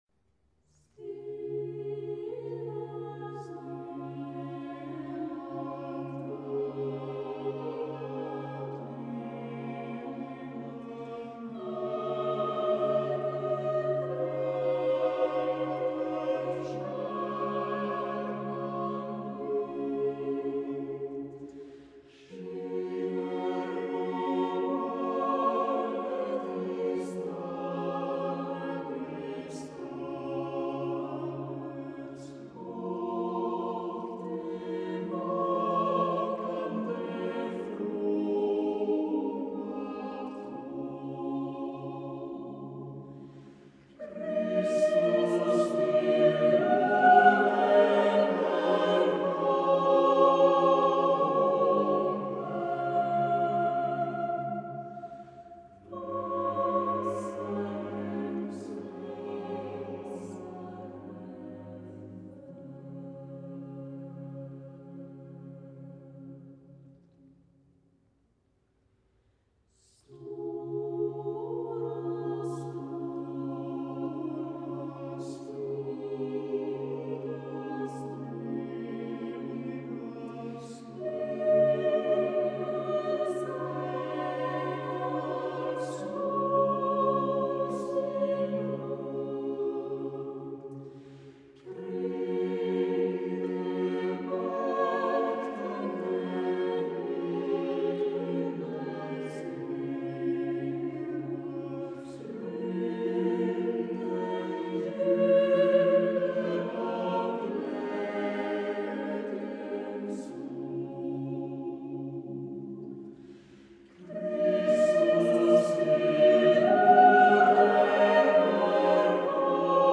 [类型]福音音乐
不管聆听纯合唱或是加入其他乐器的片段，都可以感受到教堂空间中特有的较长、温暖与圣洁的残响。